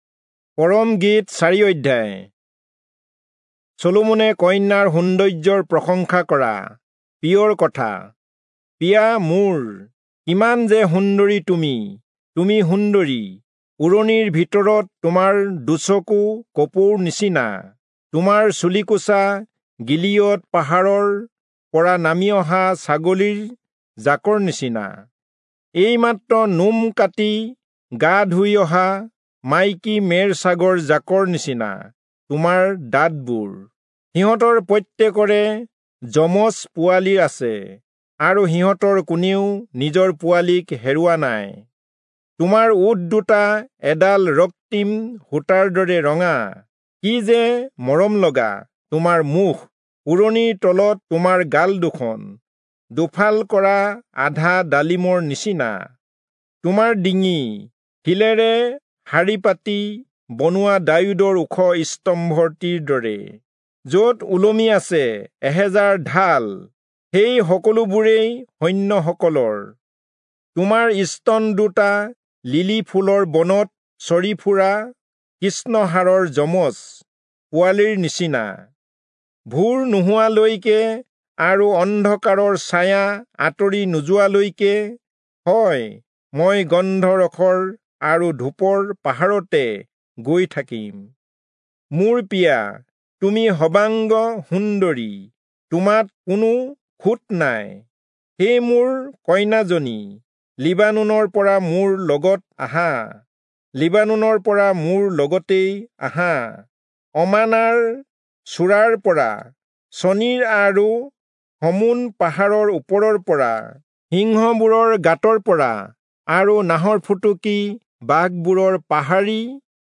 Assamese Audio Bible - Song-of-Solomon 8 in Lxxen bible version